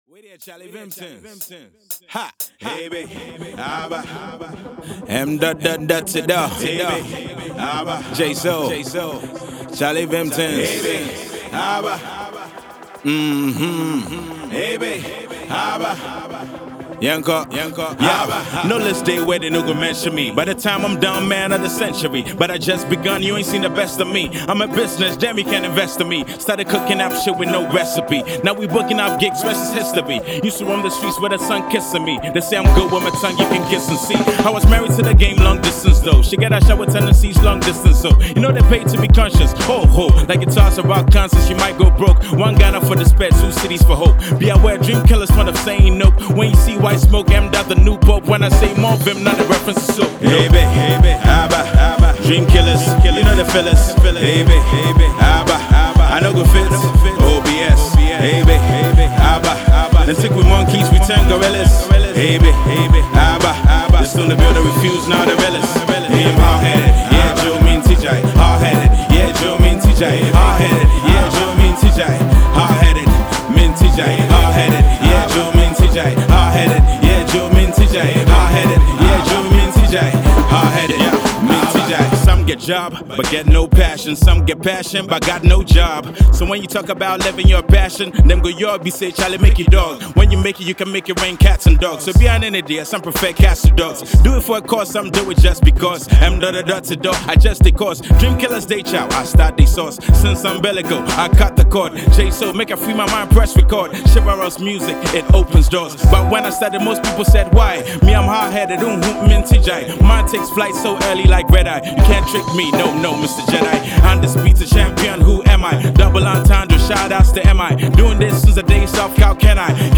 rapping crispy and clean
If you love Hip-Hop, you’ll definitely love this.